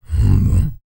TUVANGROAN13.wav